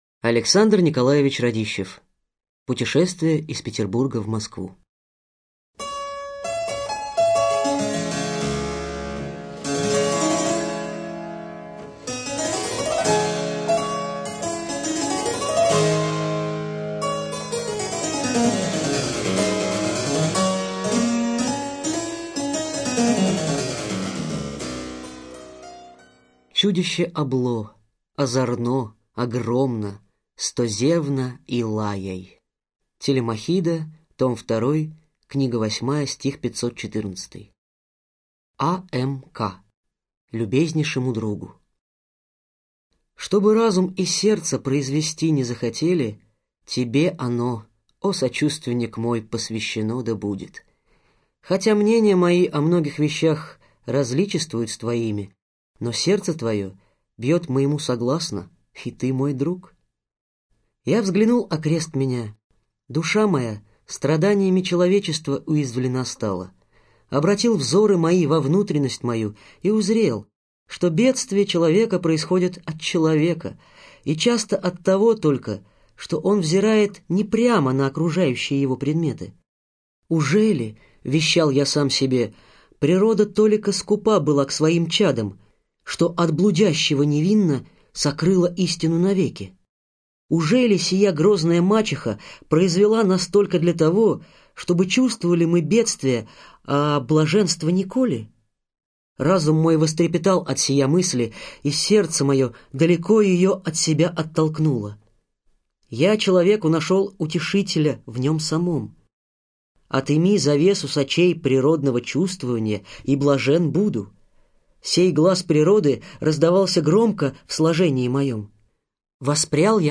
Аудиокнига Путешествие из Петербурга в Москву - купить, скачать и слушать онлайн | КнигоПоиск